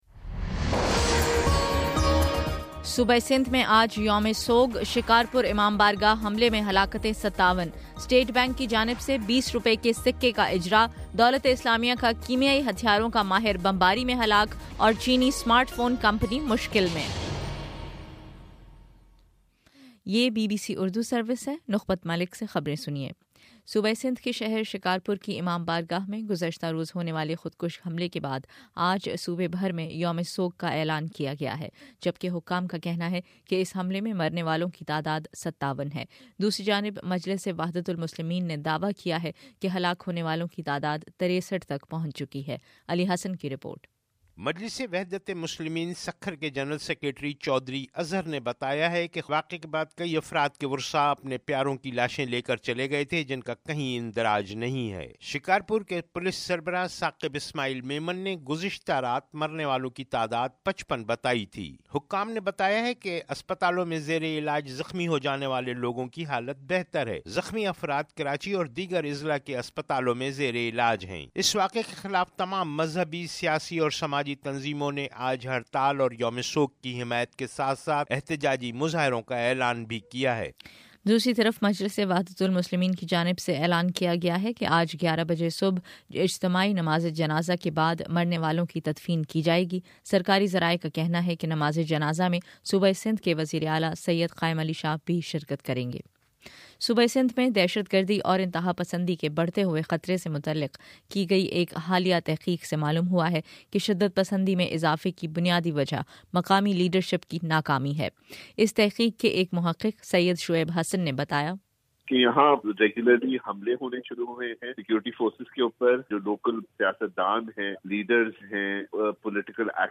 جنوری 31: صبح نو بجے کا نیوز بُلیٹن